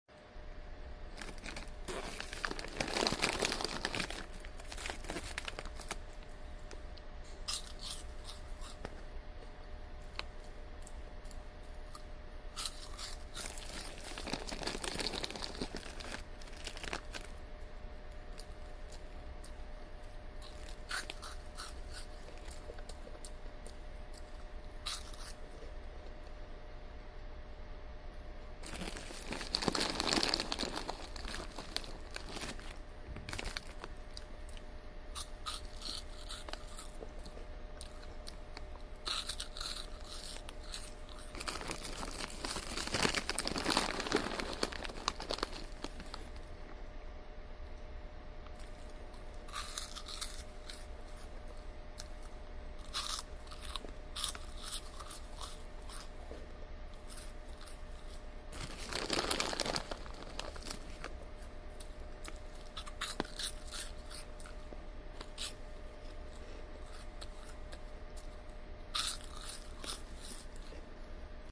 POV: You're not ai while eating crunchy pickle balls with the fan on in the background